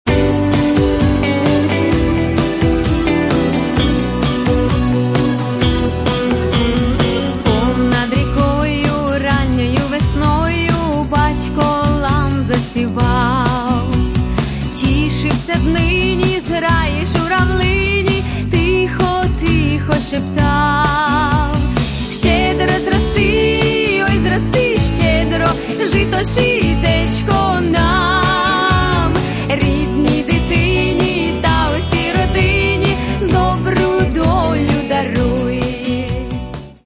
Каталог -> Естрада -> Співачки